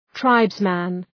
Προφορά
{‘traıbzmən}